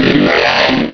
Cri de Drackhaus dans Pokémon Diamant et Perle.